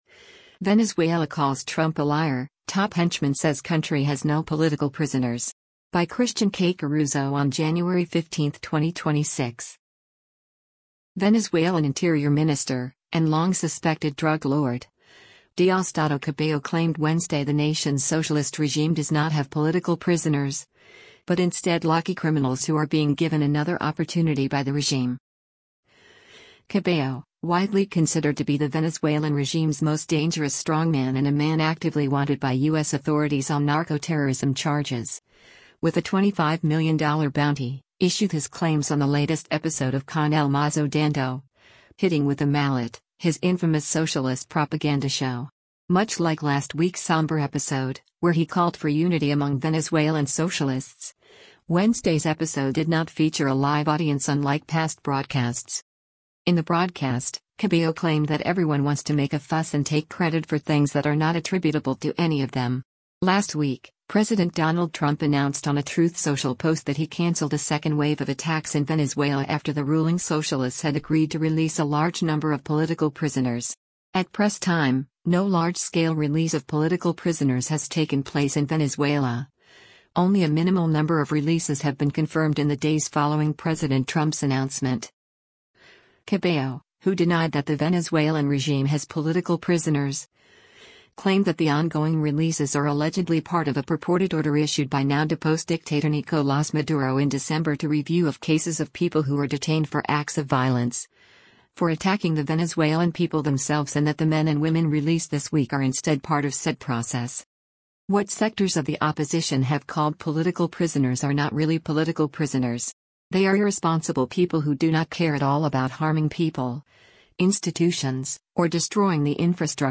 Much like last week’s somber episode, where he called for “unity” among Venezuelan socialists, Wednesday’s episode did not feature a live audience unlike past broadcasts.